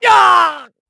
Clause_ice-Vox_Damage_kr_03.wav